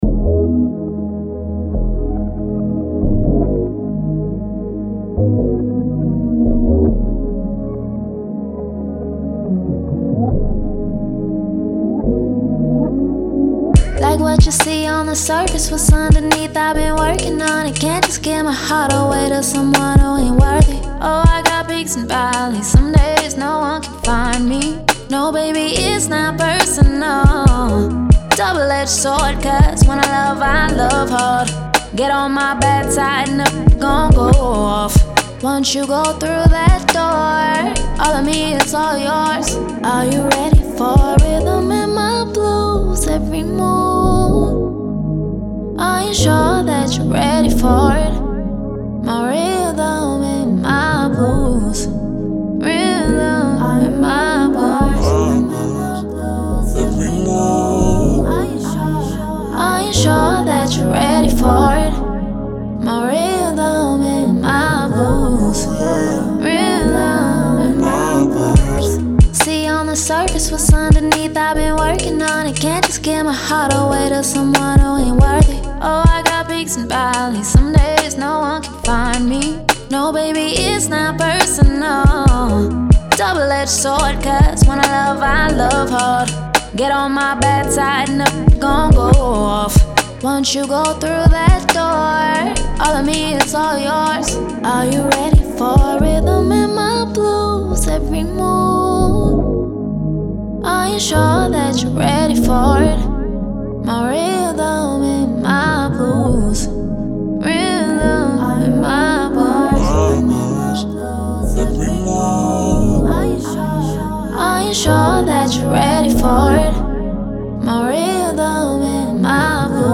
R&B
Ab Minor